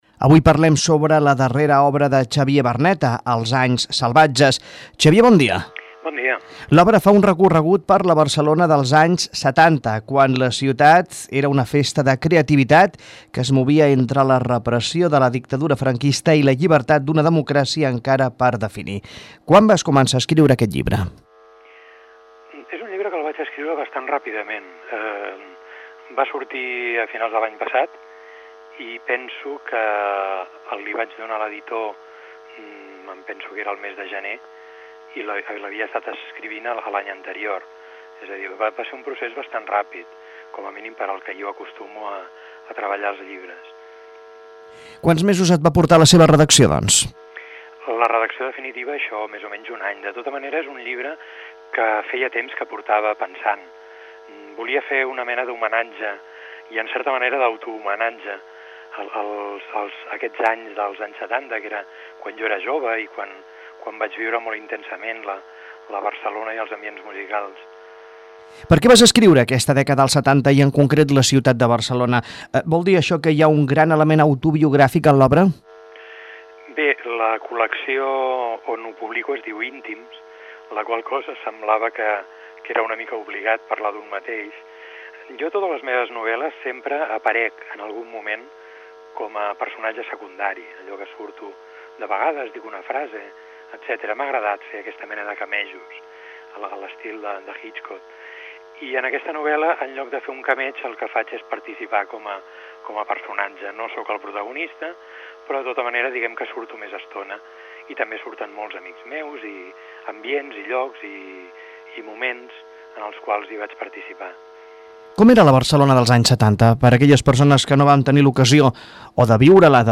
Entrevista a Vilassar Ràdio